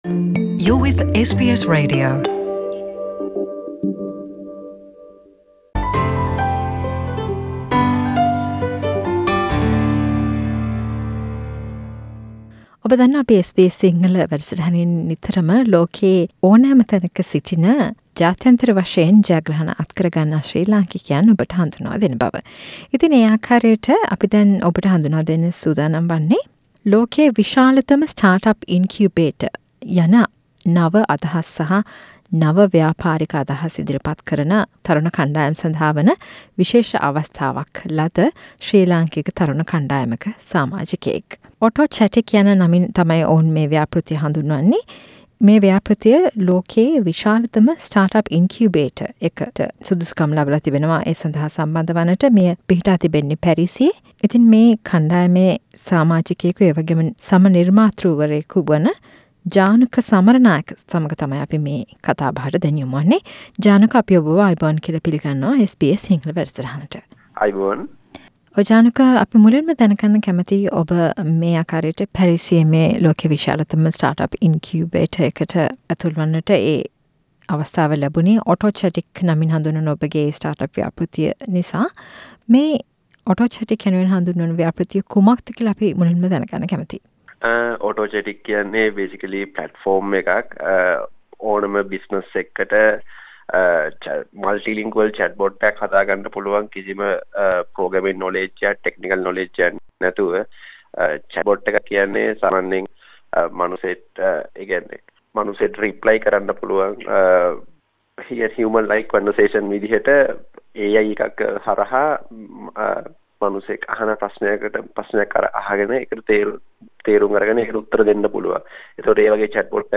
SBS සිංහල සමග කල පිලිසදර